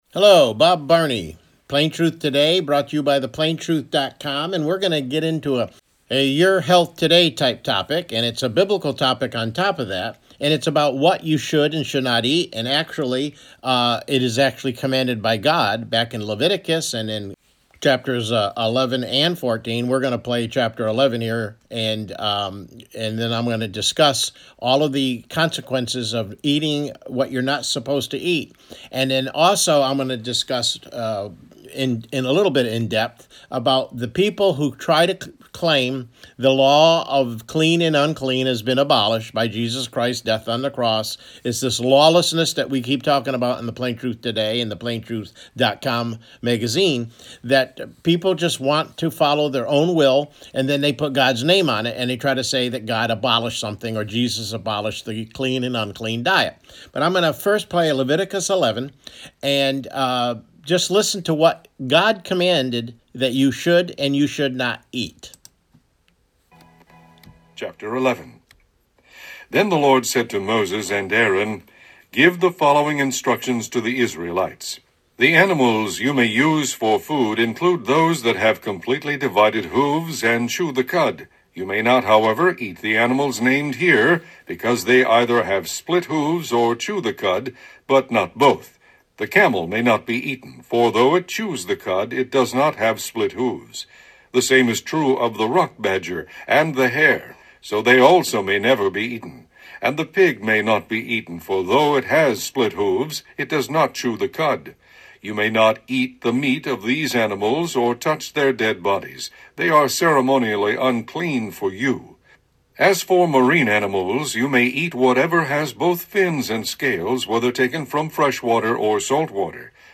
CLICK HERE TO LISTEN TO THE PLAIN TRUTH TODAY MIDDAY BROADCAST: God’s Diet